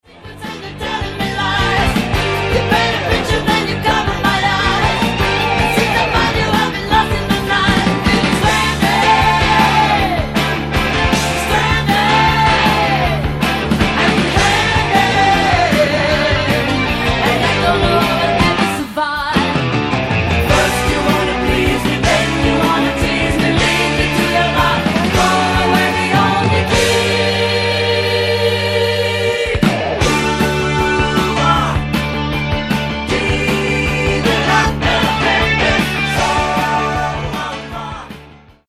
LightMellow